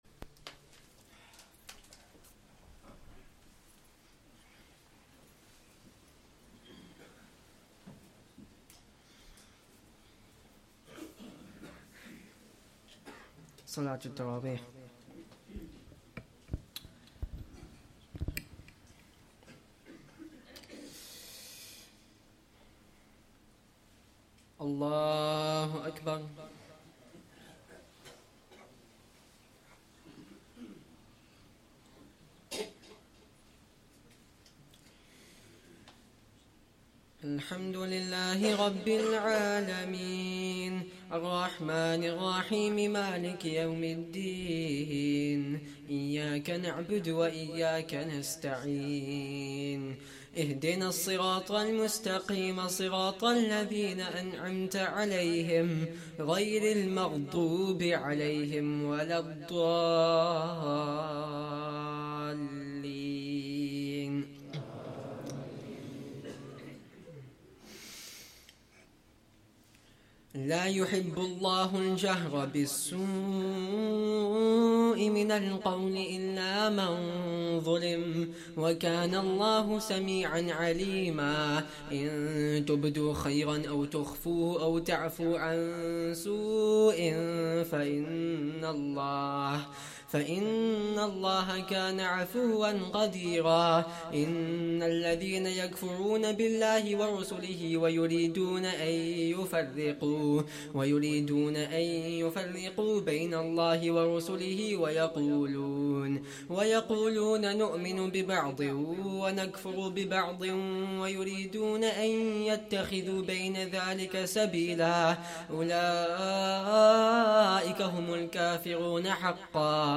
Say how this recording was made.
2nd Tarawih prayer - 5th Ramadan 2024